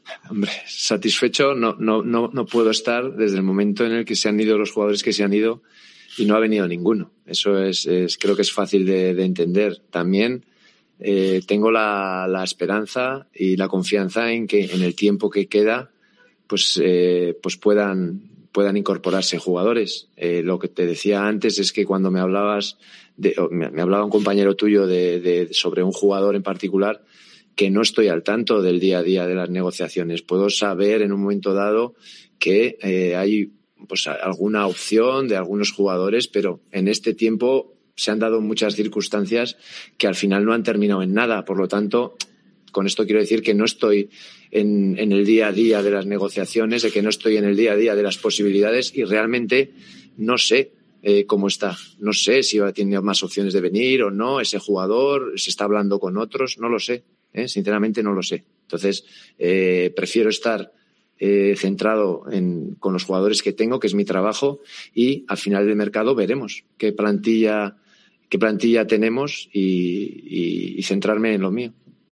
AUDIO. Así se ha pronunciado Javi Gracia sobre el mercado